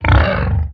1.21.4 / assets / minecraft / sounds / mob / hoglin / idle11.ogg